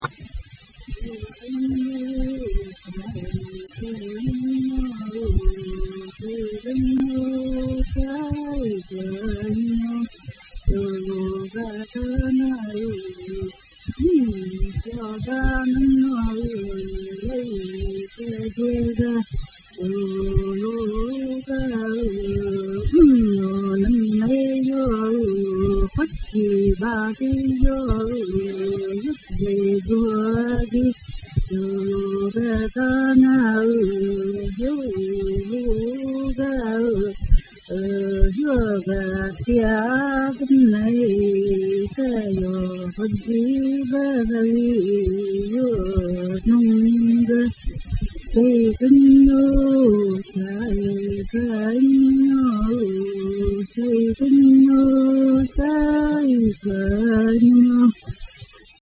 This song, as well as the next three songs, expresses memories about the olden days.